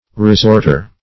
Resorter \Re*sort"er\ (-?r), n. One who resorts; a frequenter.